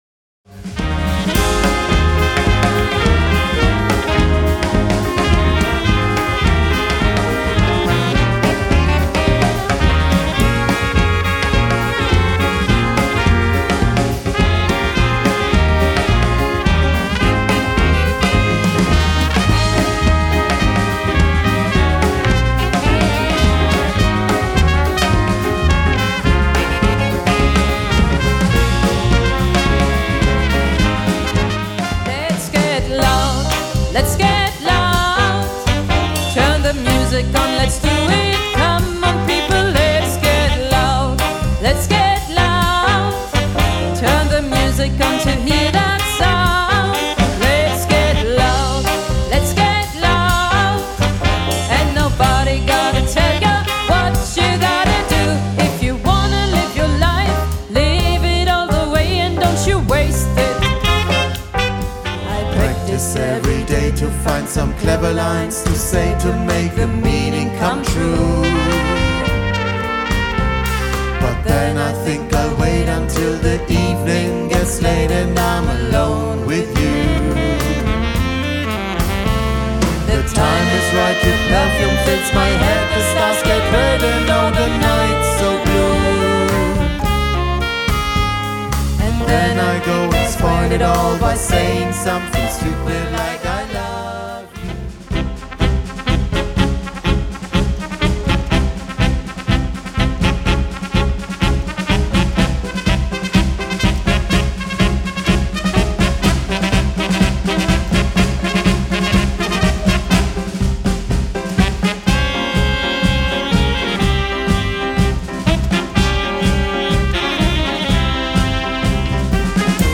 Live - Mitschnitte (Auszüge)
Tänze - Latein
1. Samba, 2. ChaCha, 3. Rumba, 4. Paso Doble, 5. Jive